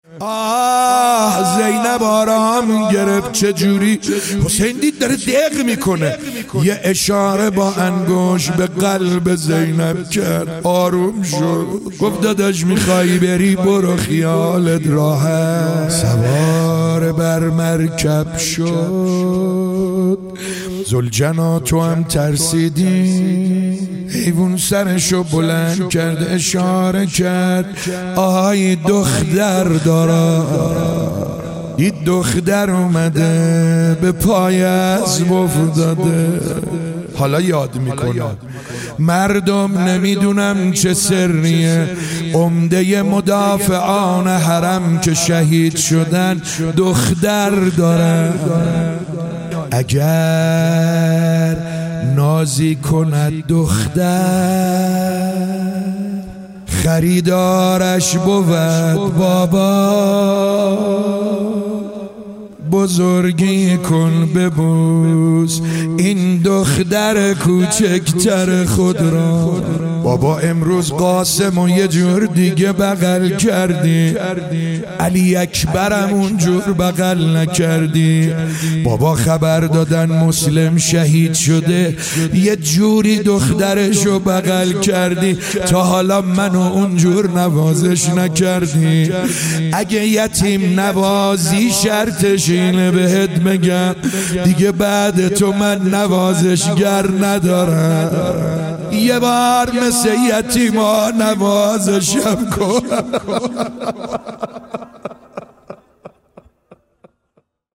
مداحی حاج سعید حدادیان | محرم الحرام 1399 هیئت رزمندگان اسلام